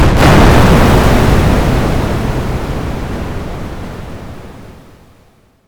big_explosion
bomb explode explosion sound effect free sound royalty free Memes